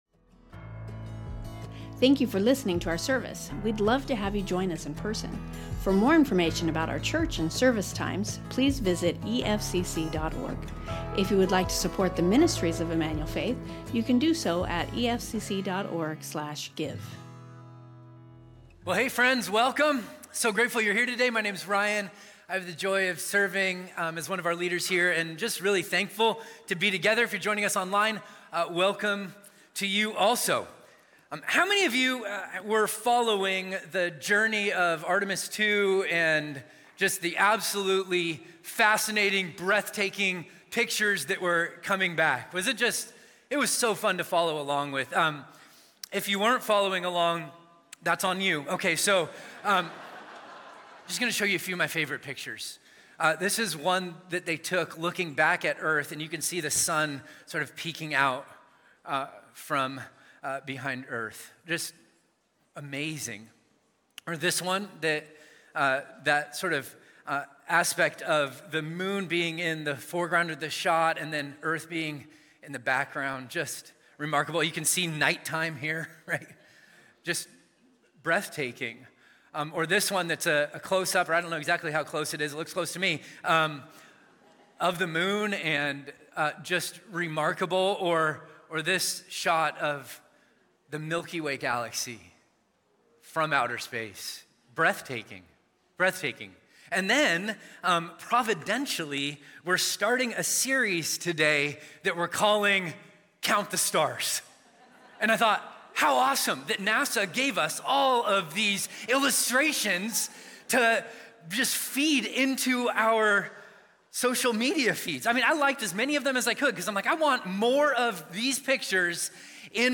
Emmanuel Faith Sermon Podcast A Dangerous Departure | Genesis 11:27–12:9 Apr 13 2026 | 00:47:51 1x 00:00 / 00:47:51 Subscribe Share Spotify Amazon Music RSS Feed Share Link Embed